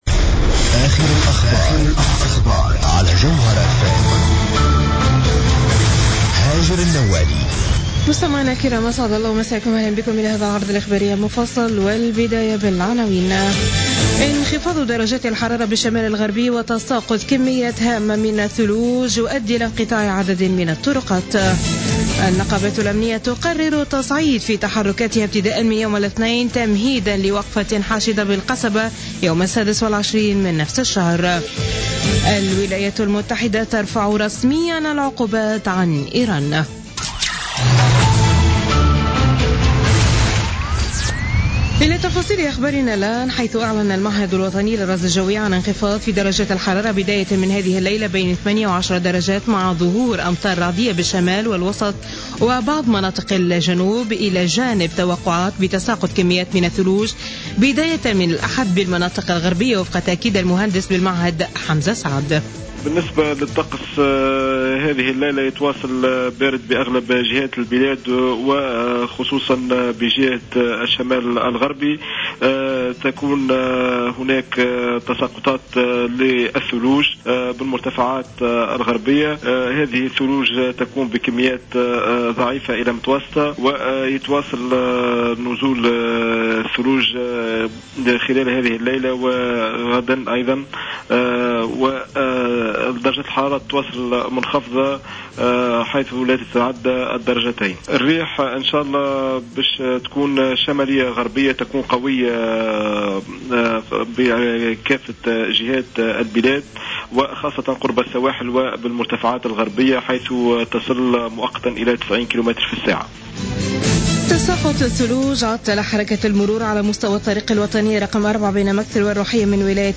Journal Info 00h00 du dimanche 17 Janvier 2016